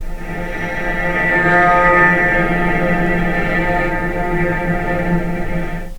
vc_sp-F3-pp.AIF